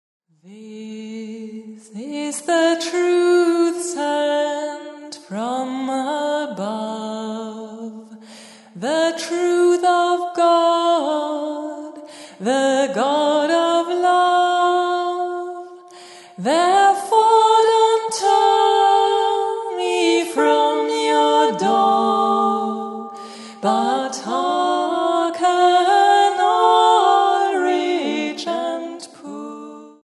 Advents- und Weihnachtsmusik